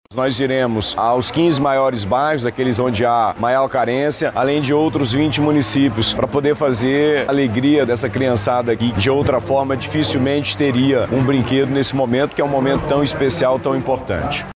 O governador do Amazonas, Wilson Lima, participou da ação e falou da ida a outras comunidades.